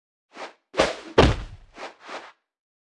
Media:anim_resort_royale_king_01.wav 动作音效 anim 查看其技能时触发动作的音效